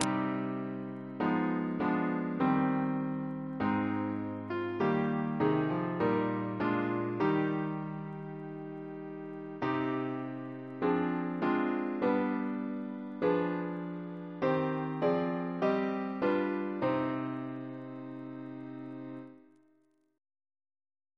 CCP: Chant sampler
Double chant in C minor Composer: Sir John Goss (1800-1880), Composer to the Chapel Royal, Organist of St. Paul's Cathedral Reference psalters: ACB: 142; ACP: 116; CWP: 102